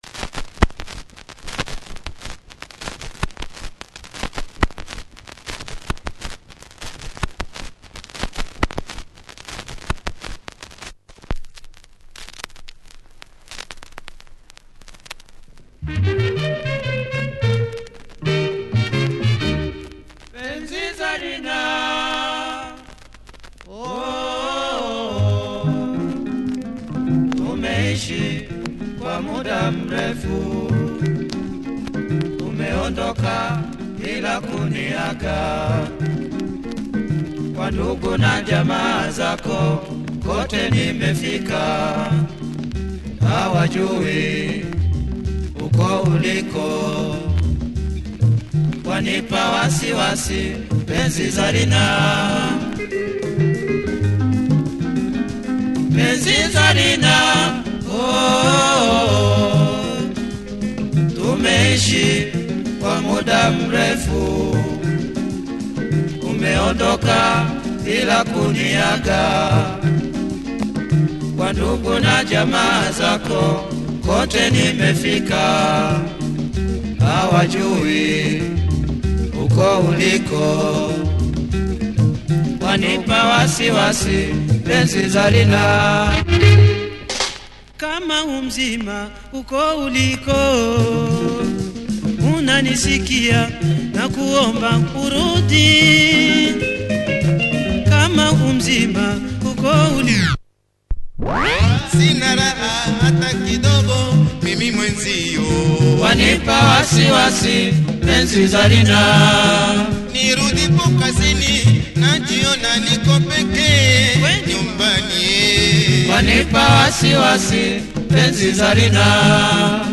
Great Tanzanian rumba! Lovely vibe and great horns.